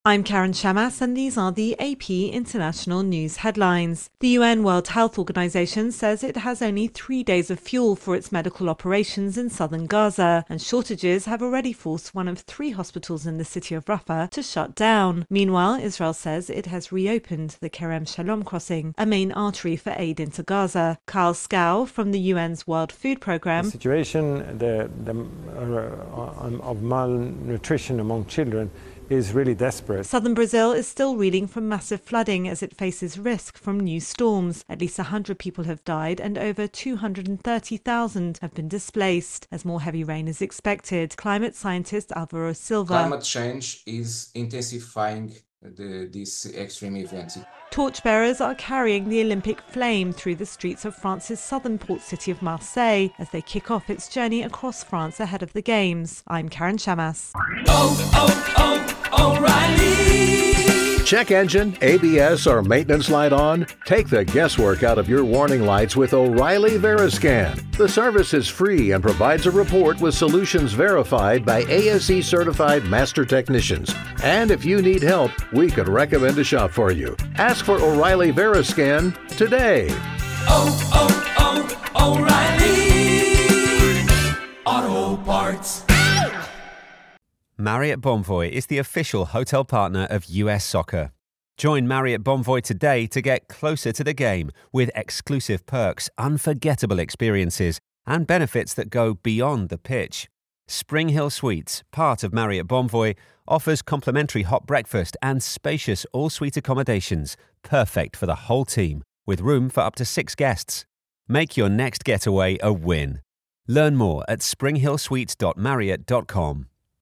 The UN says Rafah is running out of fuel, Israel reopens the Kerem Shalom aid crossing, Brazil floods continue with more rain coming, and the Olympic flame is kicking off its journey around France. AP correspondent